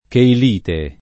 cheilite [ keil & te ]